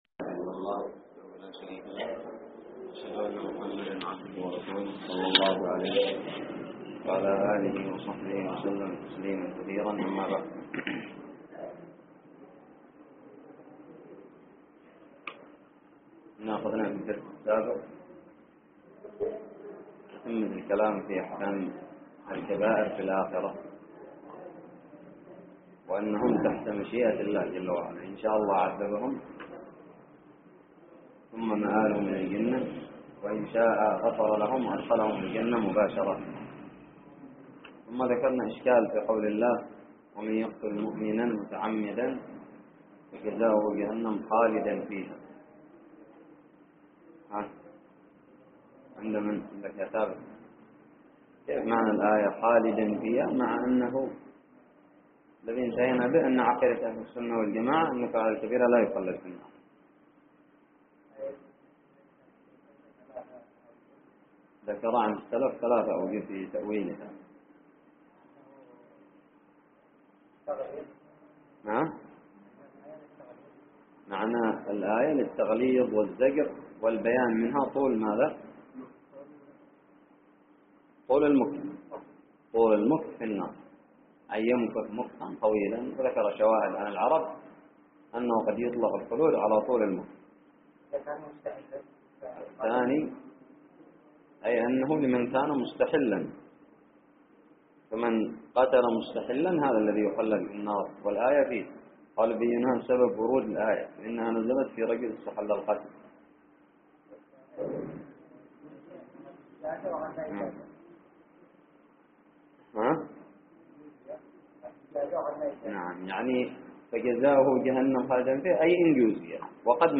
الدرس الرابع والثلاثون من شرح العقيدة الطحاوية
ألقيت في دار الحديث بدماج